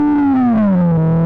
U21, audible steps and/or odd interpolated steps in engine sound, U20 brings back normal (better) sound.
Samples are stretched to twice their original length.